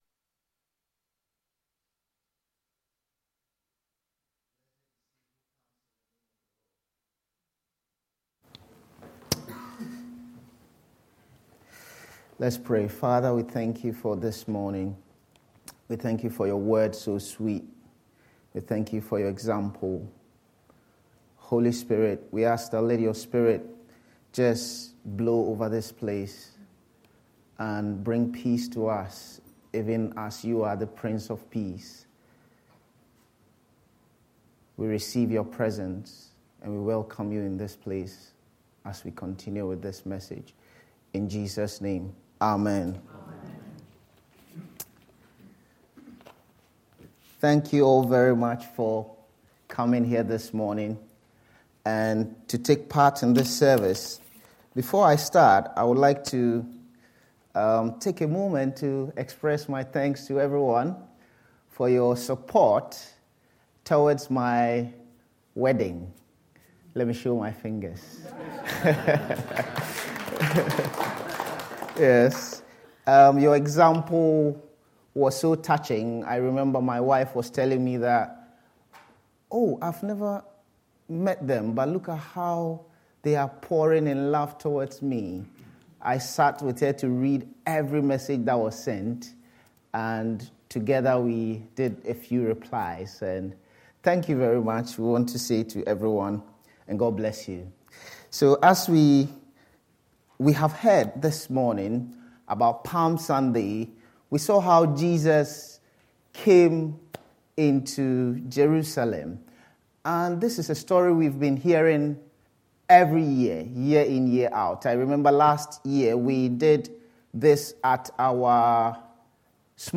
Palm-Sunday-Service.mp3